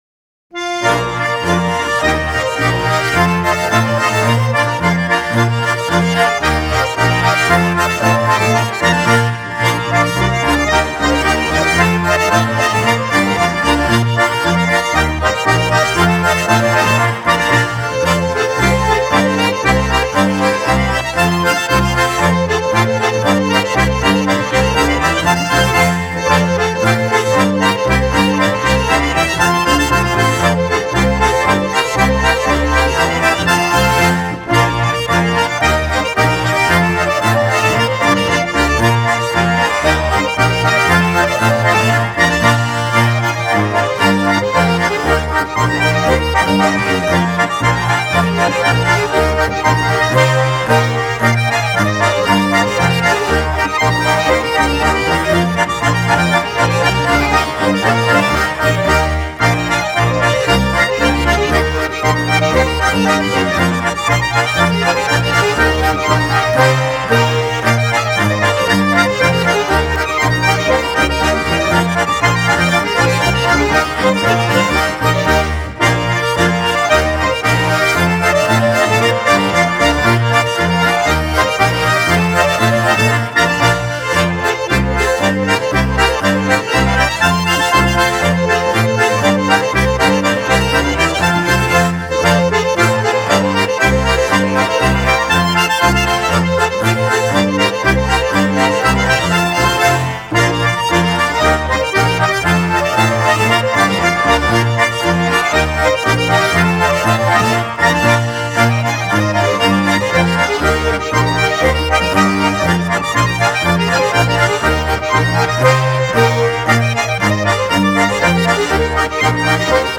Schottisch
Tonstudio Braun, Küsnacht